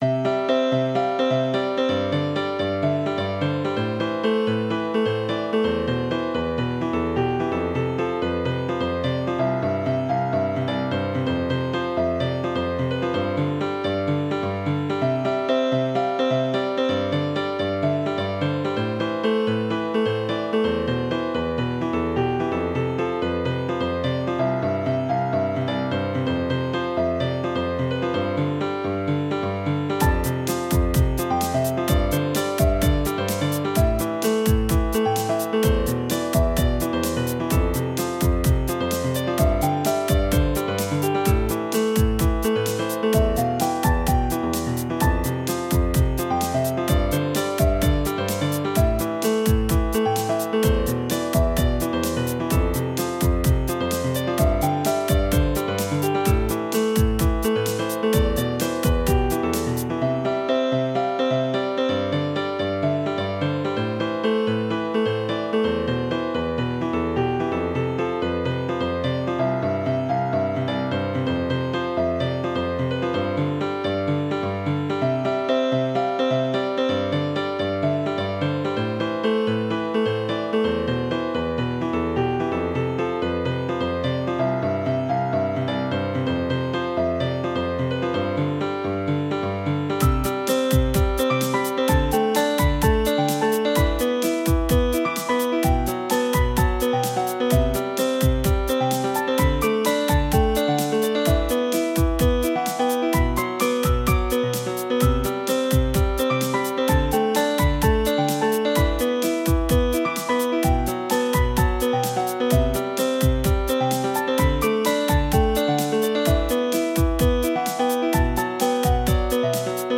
初めての変拍子曲です
・3拍子だから